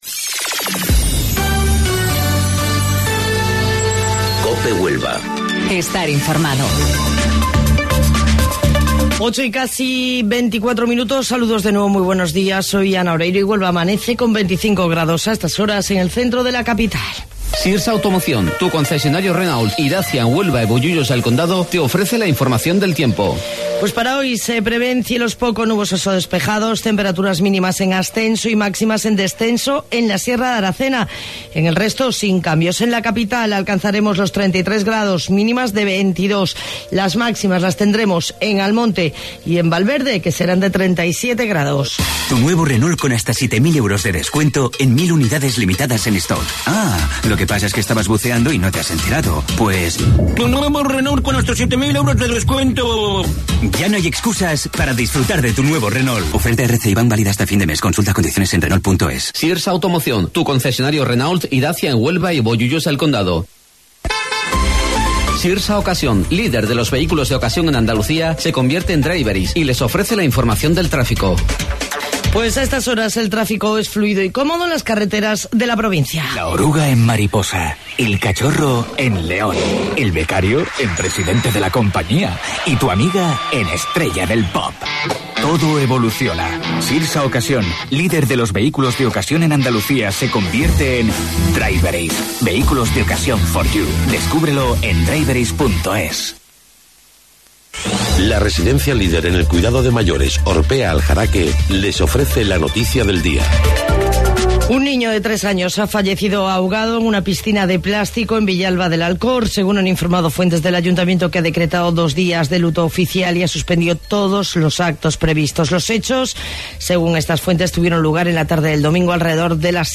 AUDIO: Informativo Local 08:25 del 23 de Julio